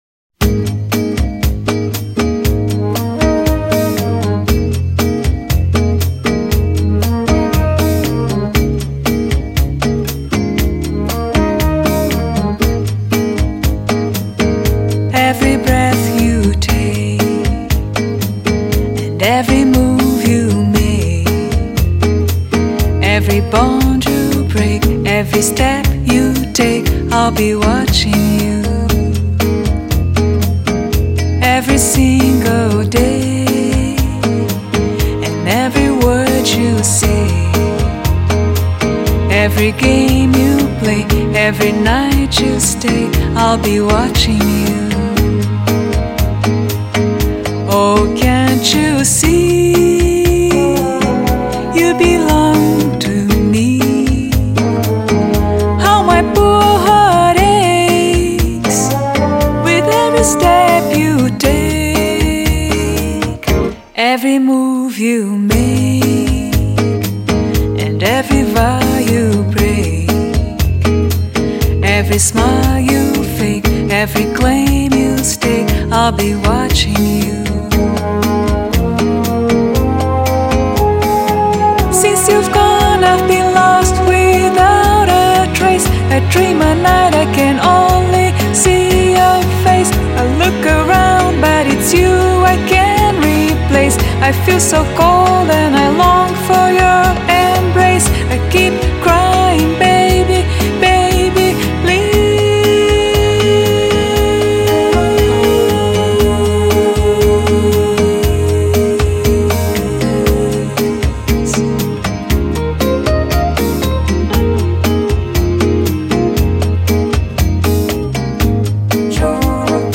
音樂類型 : 爵士樂  Bossa Nova[center]